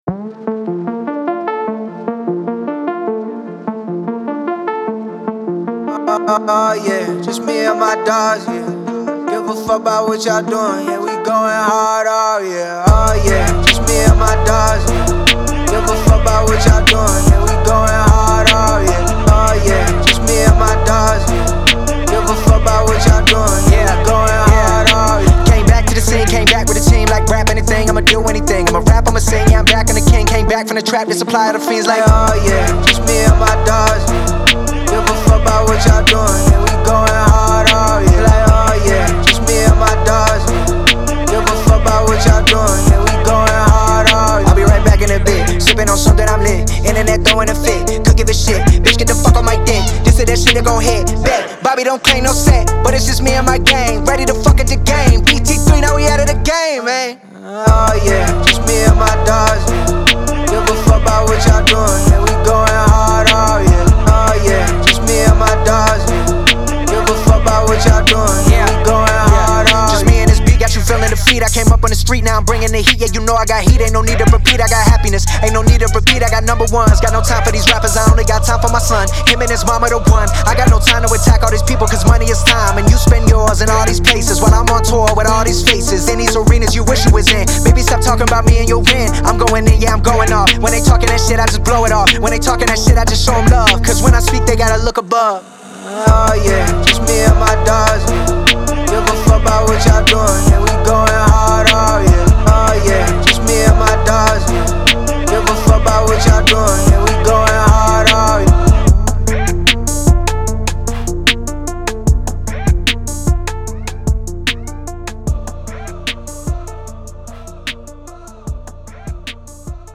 сочетая быстрые рифмы с эмоциональными текстами.